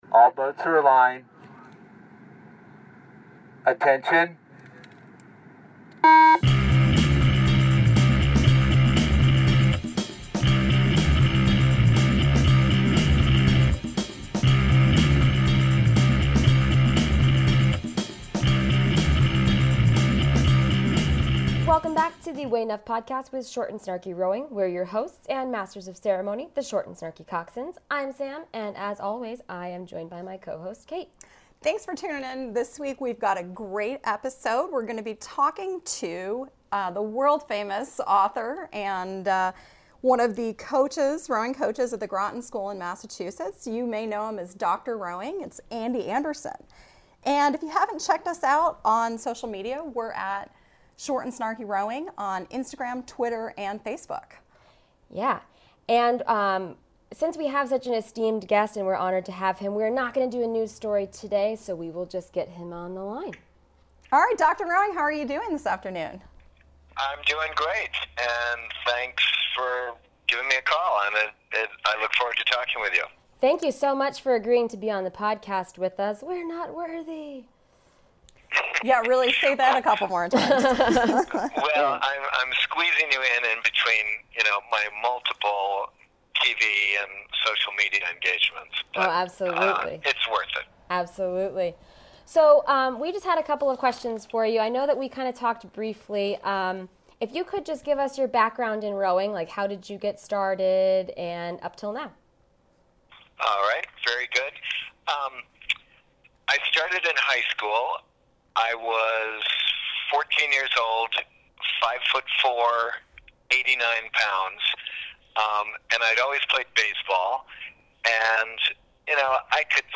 We interview: the one...the only...